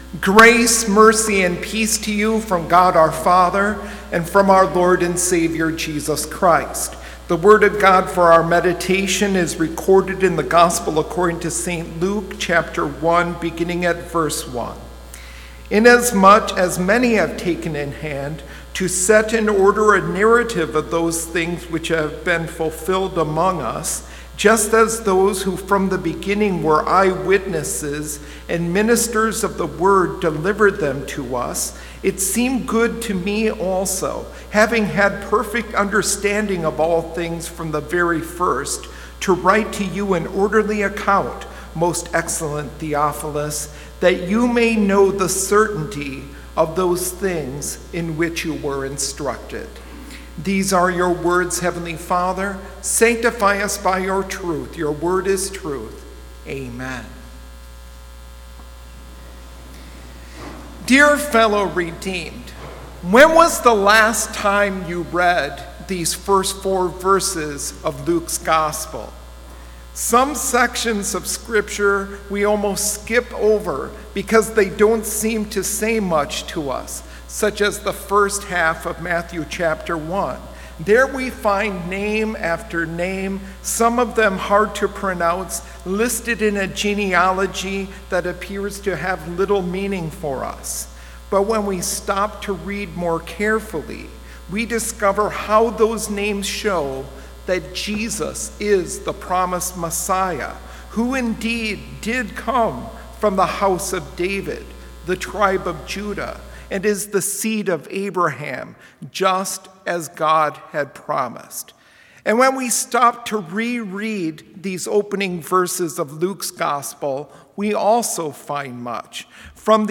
Complete service audio for Chapel - October 18, 2021